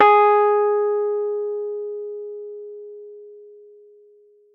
Rhodes_MK1